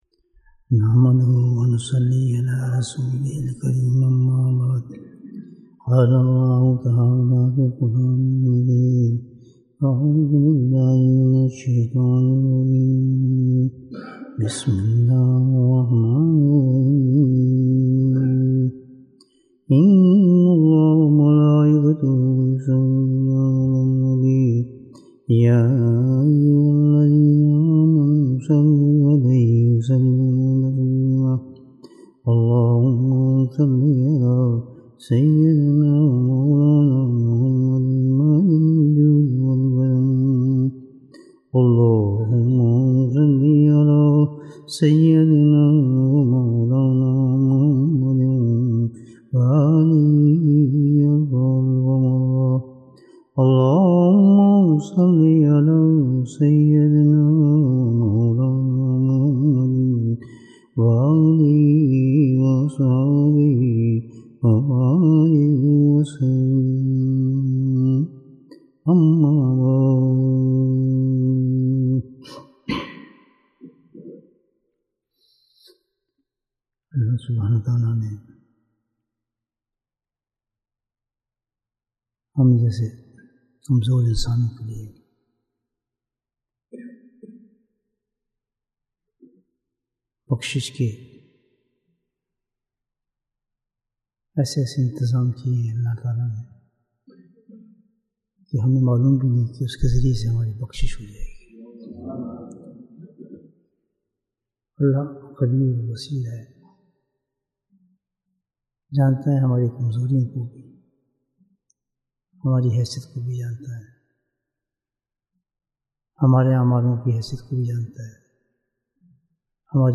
Bayan, 61 minutes15th December, 2022